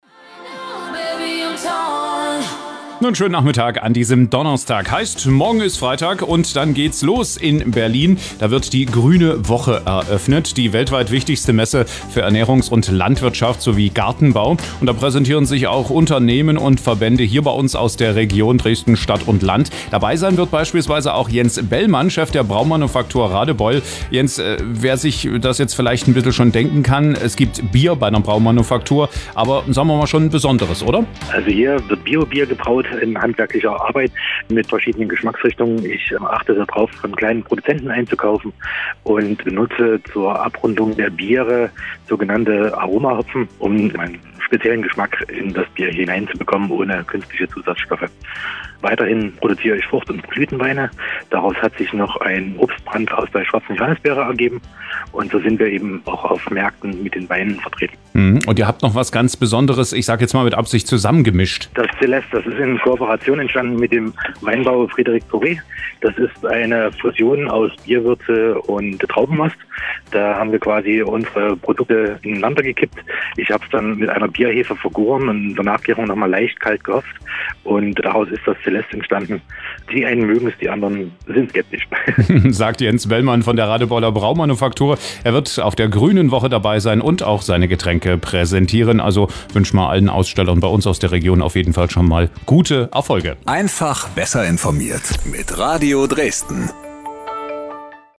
Mitschnitt_Braumanufaktur_Gruene_Woche_2020.mp3